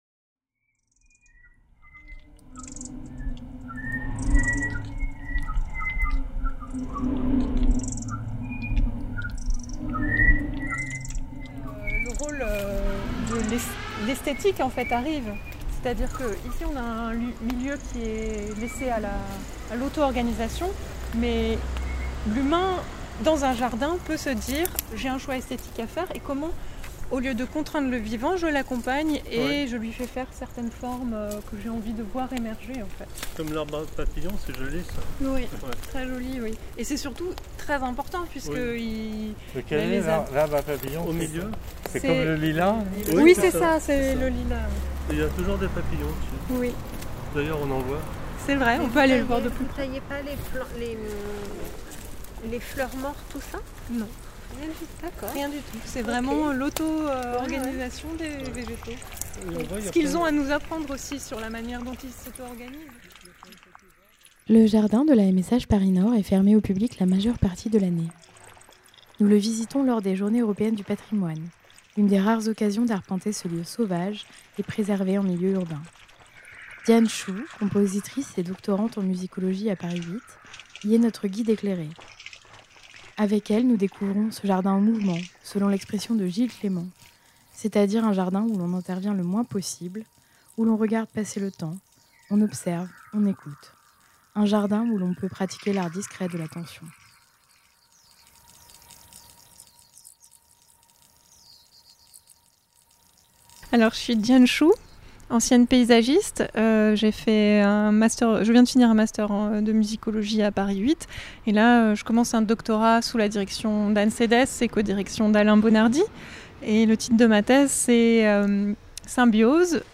Balade sonore au jardin de la MSH Paris Nord
Le jardin de la MSH Paris Nord est fermé au public la majeure partie de l’année. Nous le visitons lors des journées européennes du Patrimoine, une des rares occasions d’arpenter ce lieu sauvage et préservé en milieu urbain.